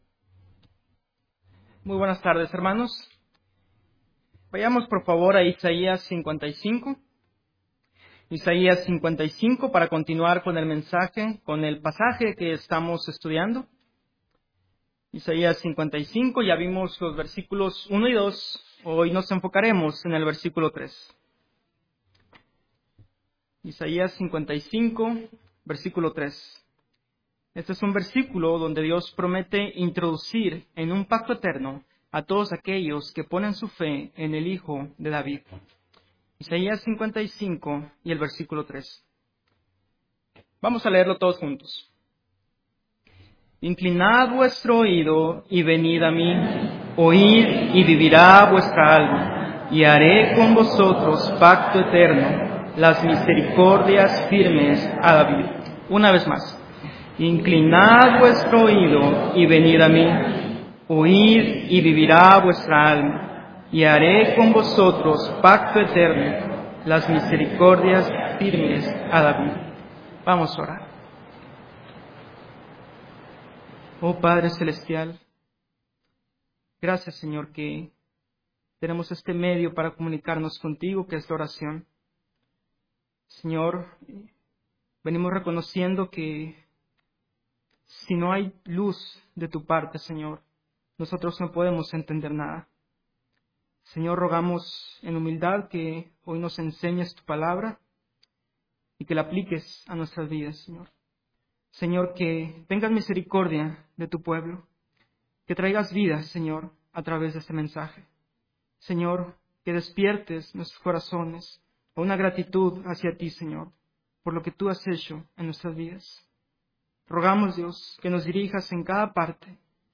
Sermón Entra en pacto con Dios , 2024-08-11